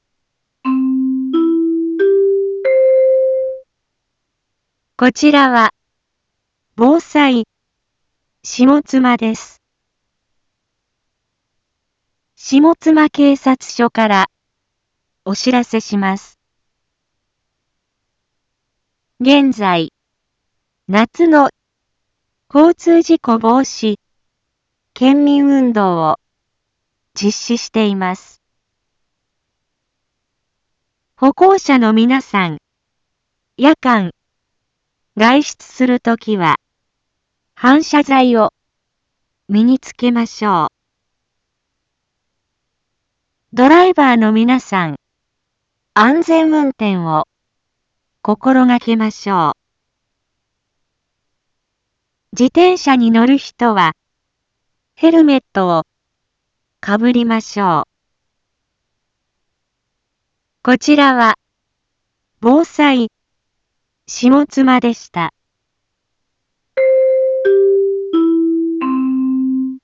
Back Home 一般放送情報 音声放送 再生 一般放送情報 登録日時：2025-07-19 17:31:29 タイトル：交通事故防止広報 インフォメーション：こちらは、ぼうさいしもつまです。